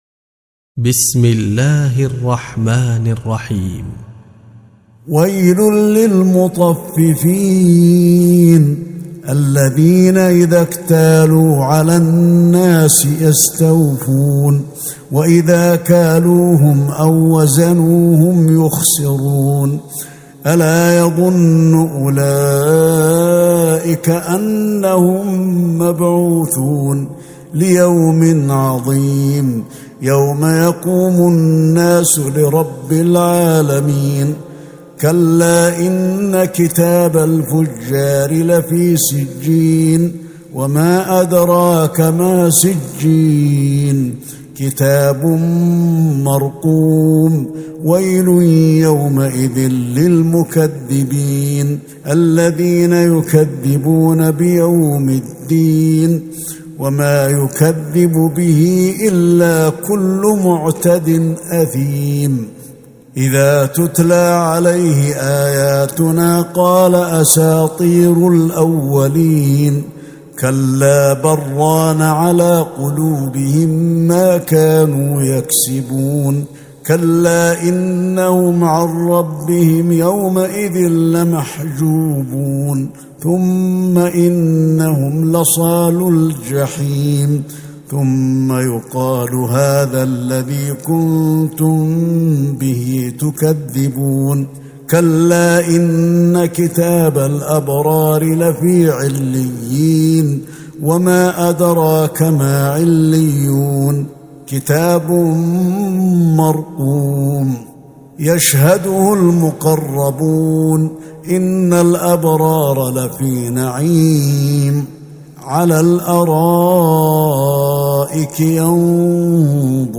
سورة المطففين Surat Al-Muttaffifeen من تراويح المسجد النبوي 1442هـ > مصحف تراويح الحرم النبوي عام 1442هـ > المصحف - تلاوات الحرمين